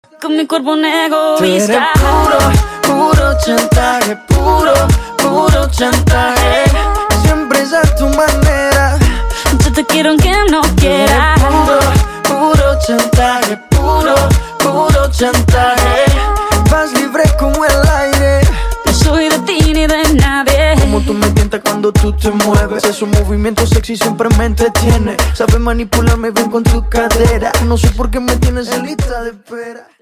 • Качество: 128, Stereo
веселые
задорные
латина